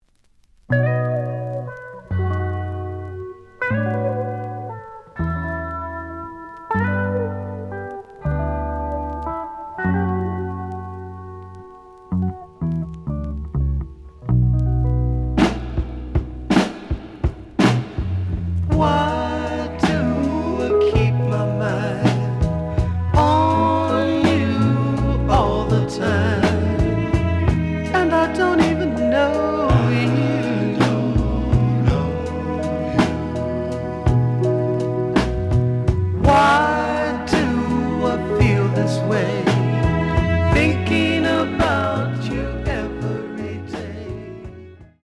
The audio sample is recorded from the actual item.
●Genre: Soul, 70's Soul
Some sound cracking on later half of B side.